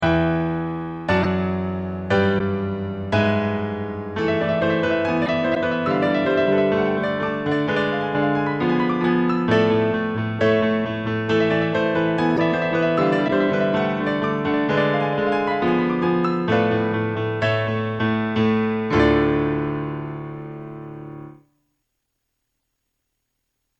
Piano
戦いっぽい曲2
P.Sまぁ音が悪いのは、レコードのせいだと好意的に解釈してください。